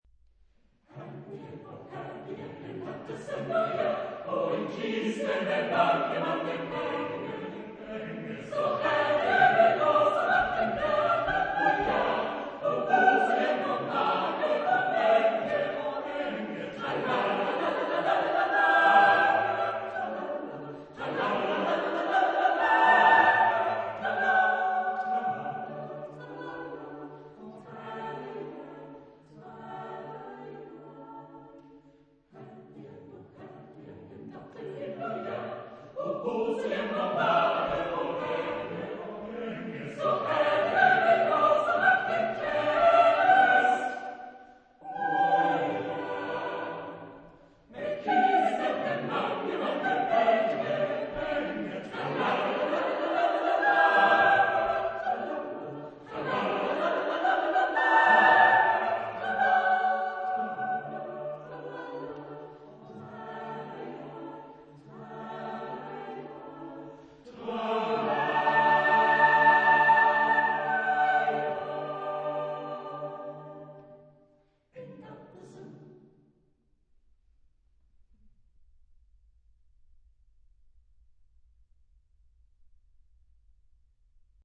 Genre-Stil-Form: Chorlied ; Zyklus ; weltlich
Chorgattung: SAATTB  (6 gemischter Chor Stimmen )
Tonart(en): D-Dur
Aufnahme Bestellnummer: Internationaler Kammerchor Wettbewerb Marktoberdorf